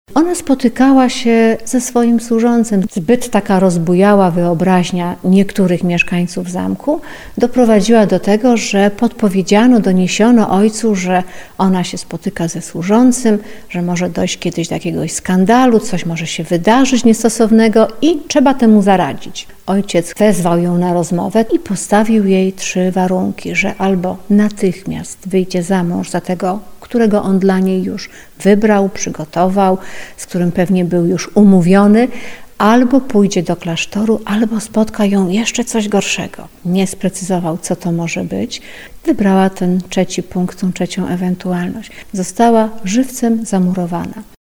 Zamek w Dębnie z mikrofonem odwiedziliśmy dziś (18.11) w audycji 'Cudze chwalicie, swego nie znacie.